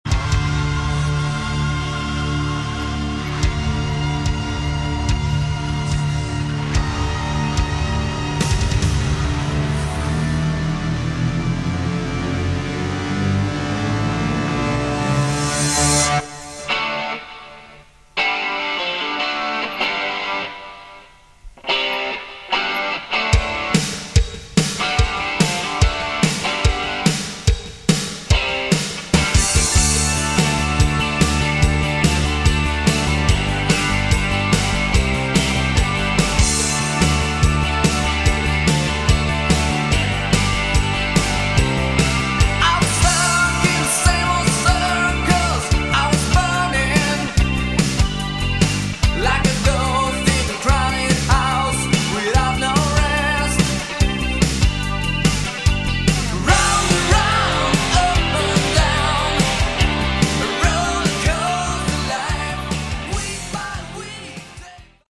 Category: Hard Rock
Vocals, Guitar, Bass, Keyboards, Drums